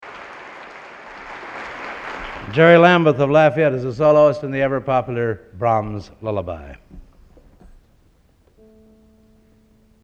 Collection: End of Season, 1964
Location: West Lafayette, Indiana
Genre: | Type: Director intros, emceeing |End of Season